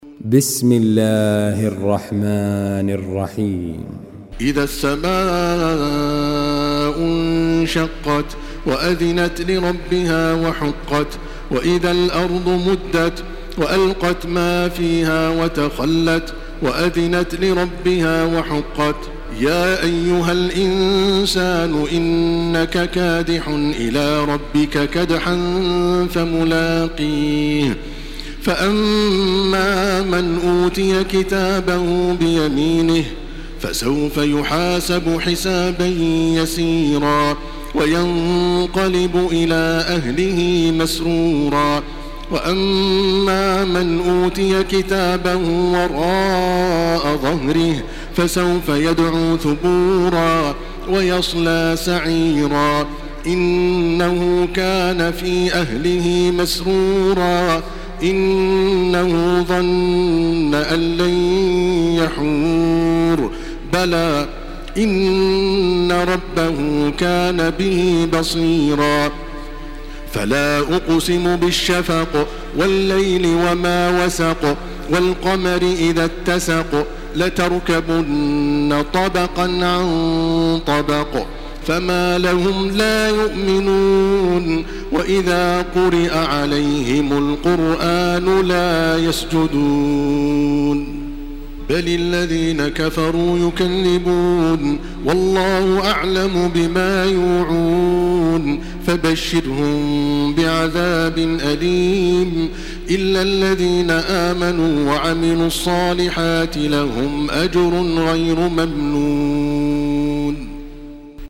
Surah Inşikak MP3 by Makkah Taraweeh 1429 in Hafs An Asim narration.
Murattal